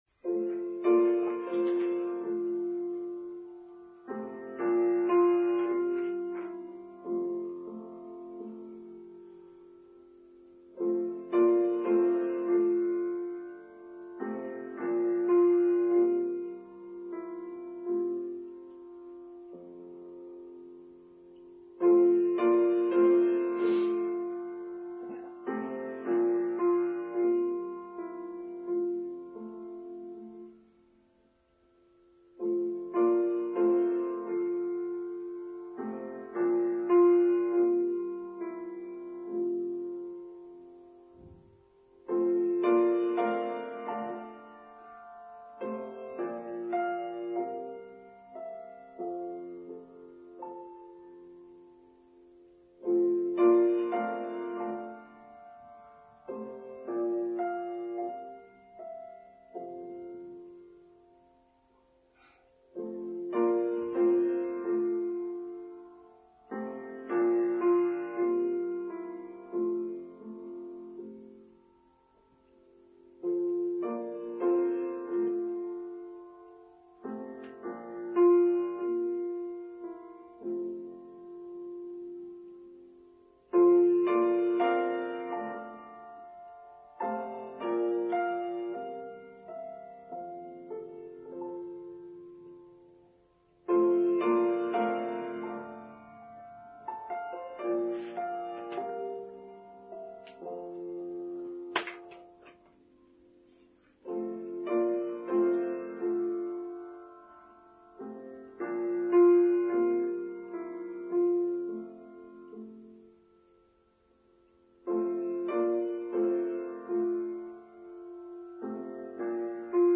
Mary Magdalene: Online Service for Sunday 23rd July 2023